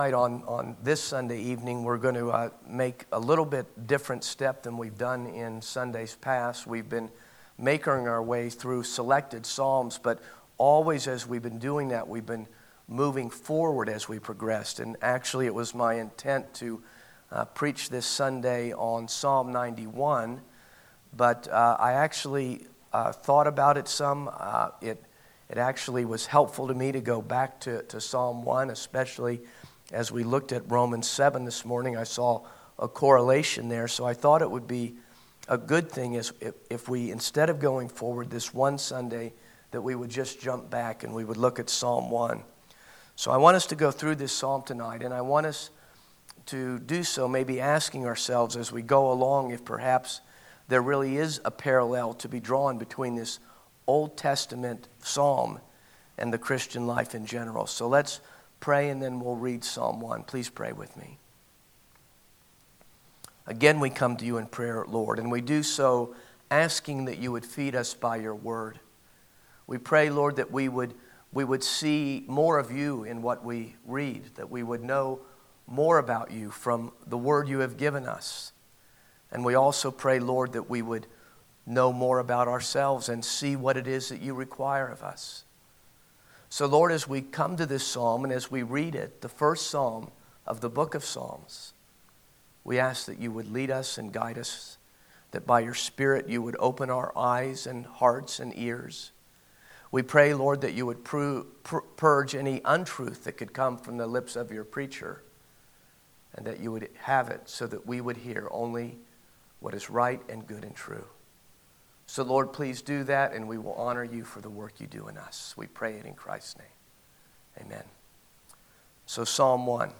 Audio Sermon